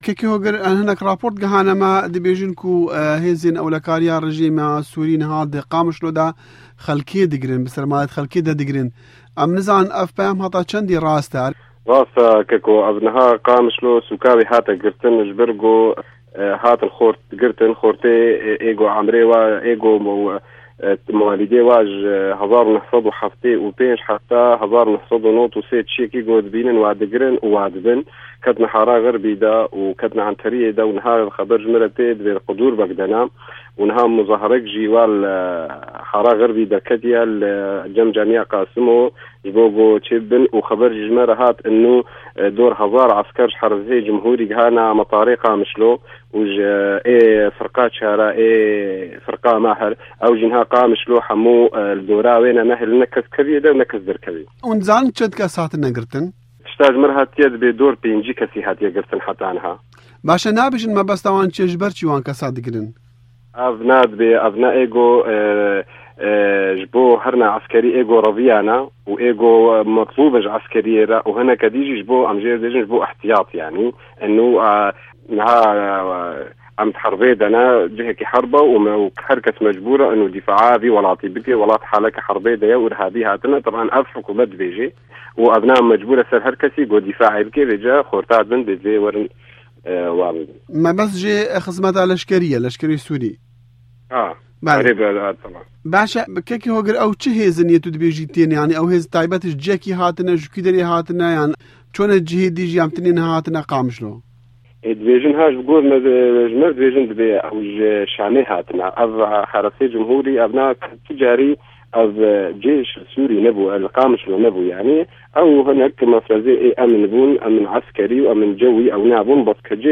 Hevpeyv'în